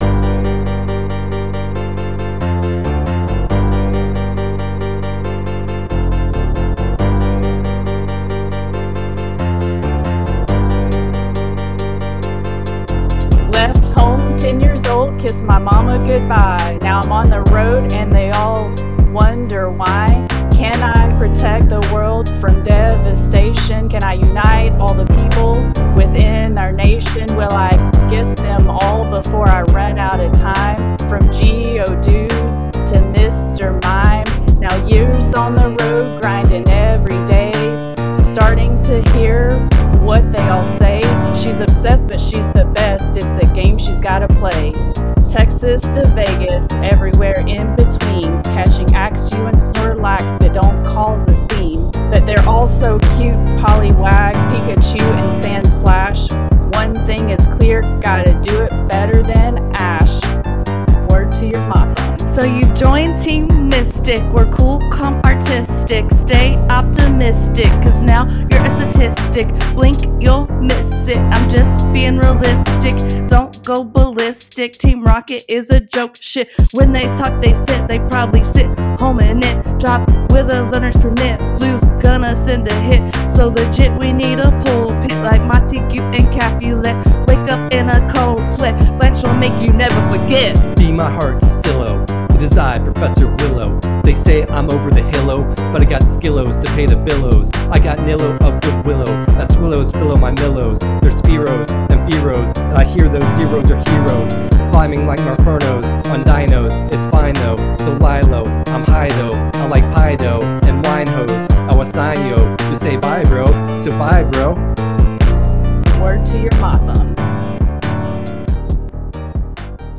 Rap from Episode 60: Pokemon Go – Press any Button
Pokemon-Go-rap.mp3